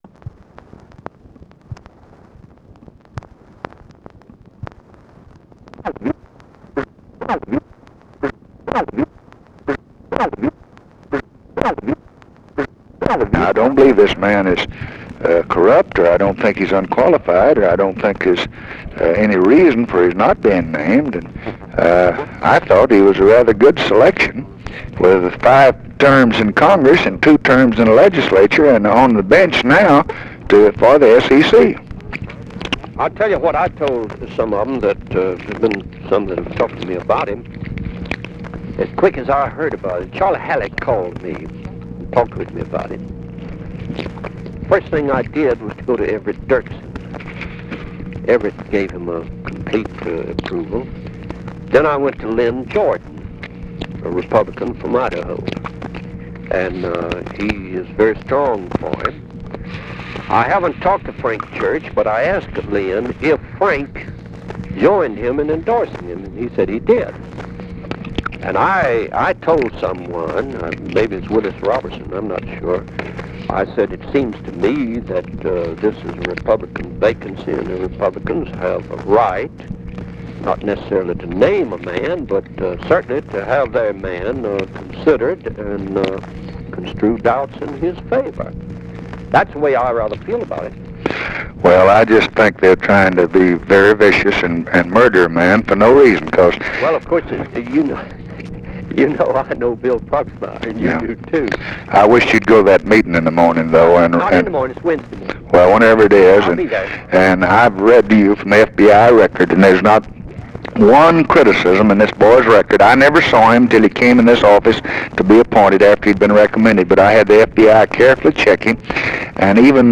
Conversation with JOHN SPARKMAN, June 22, 1964
Secret White House Tapes